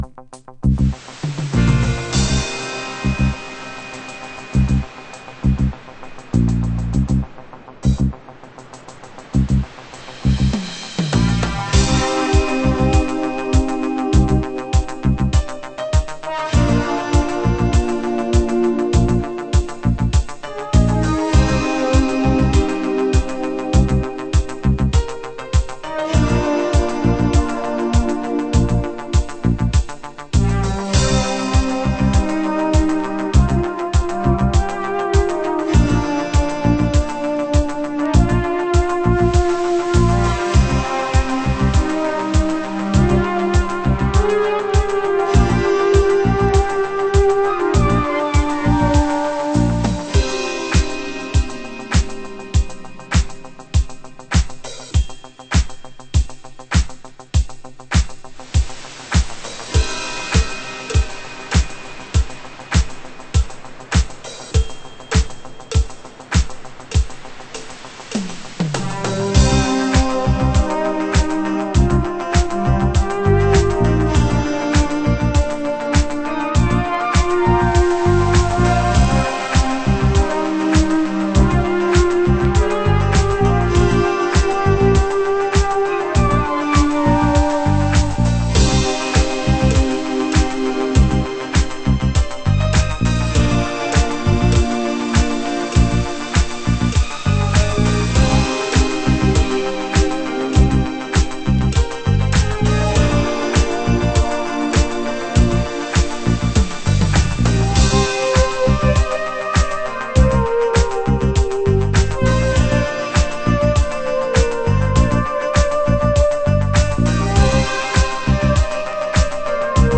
DISCODUB